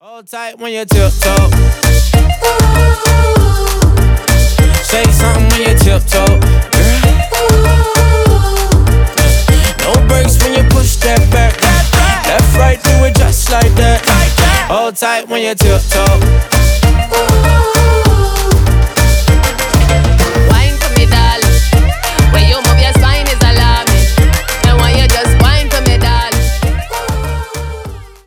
Поп Музыка # Танцевальные
весёлые